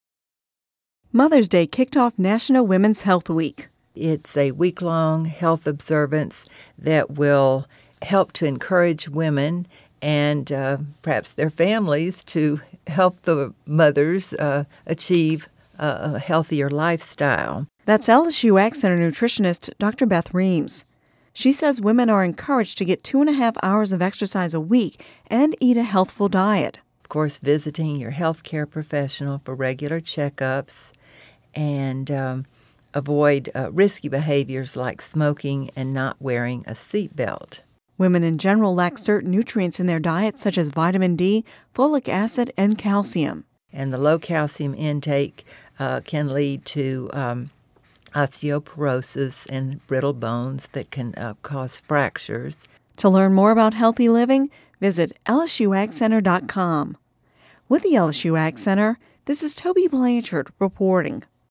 (Radio News 05/10/10) Mother’s Day kicked off National Women’s Health Week.